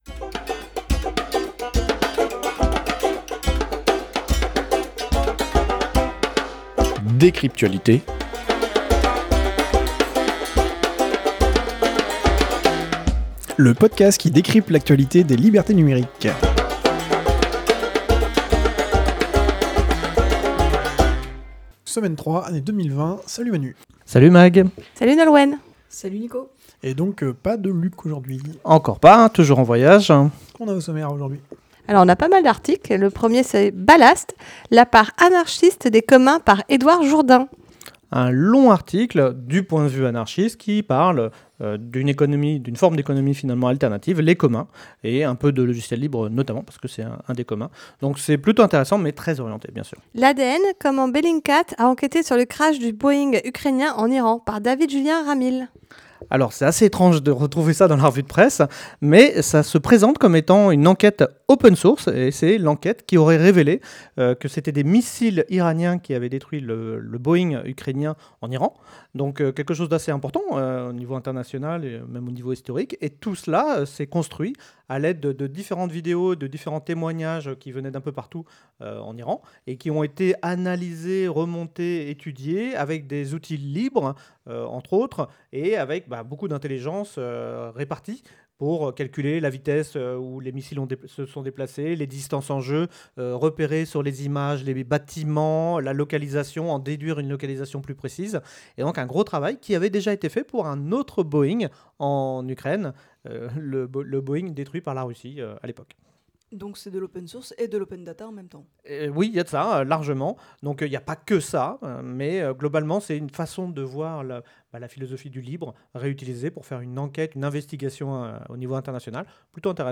Lieu : April - Studio d'enregistrement